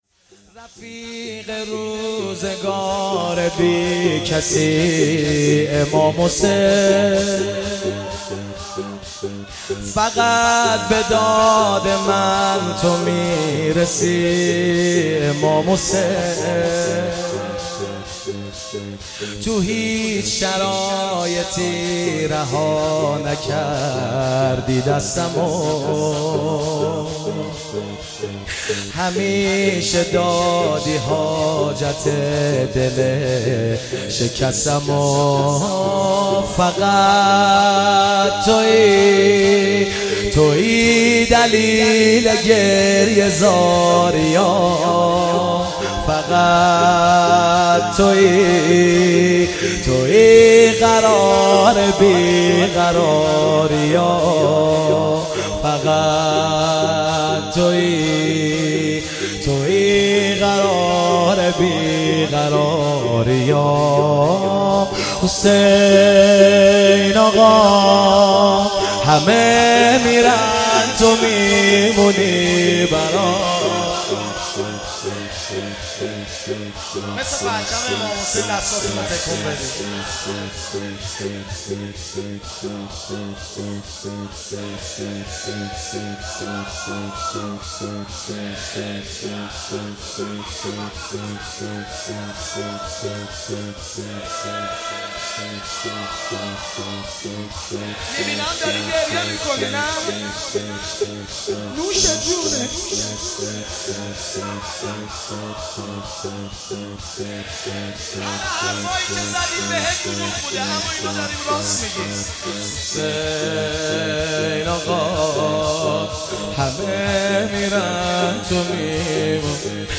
شب پنجم محرم98 هیئت ابوالفضل شاه جهان آباد میبد
شور